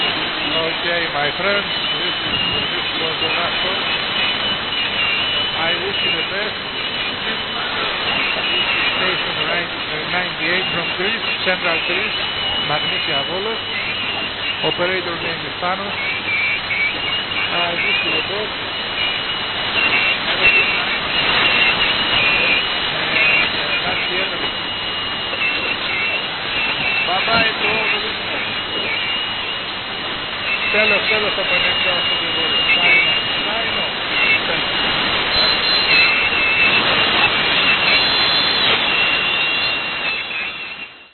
I heard Greek folk music and talking in English:
The frequency was 1720 kHz and power of the transmitter was 1 kW.